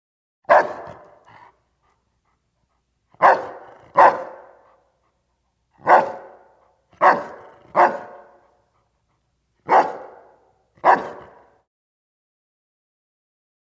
DogDobermanBarksLo AT020801
Dog, Doberman Barks. Low Pitched, Reverberant, Snarl-like Barks With Light Pants. Close Perspective.